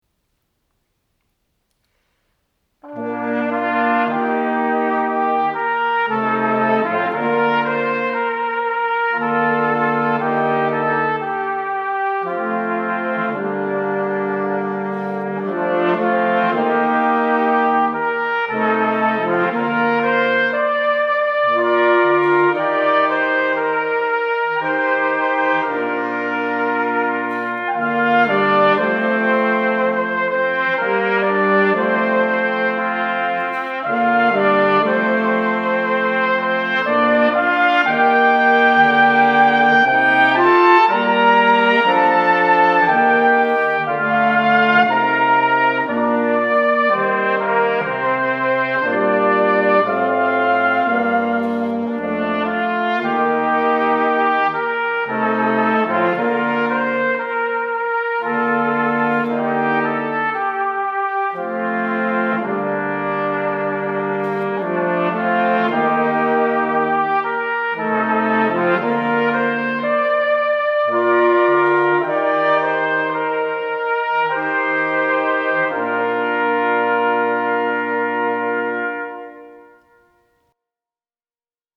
Ensemble 4 voix Flex
Classic Up to Date / Musique classique